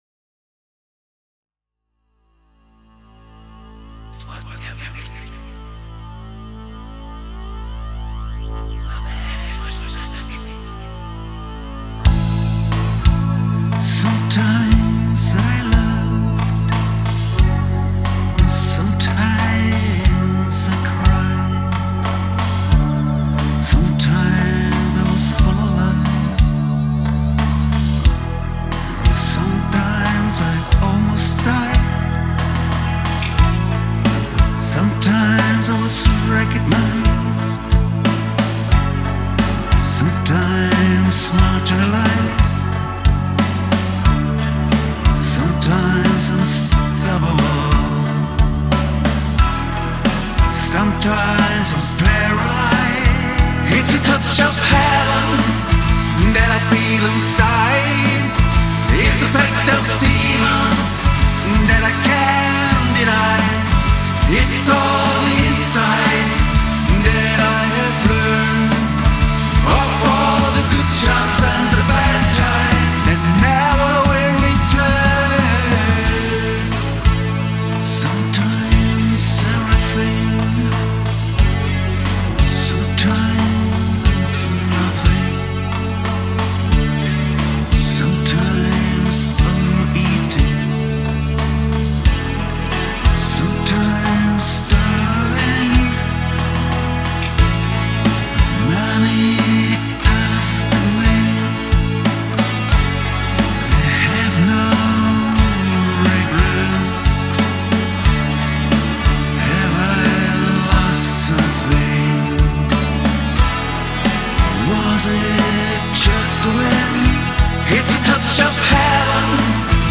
Ich habe mich mit Home-Recording und eigenen Songs beschäftigt.
Und ich befand mich in Isolation, in meinem Musikzimmer.
Und weil ich niemanden dazu holen durfte, hab ich diesmal halt mal alles selbst gemacht.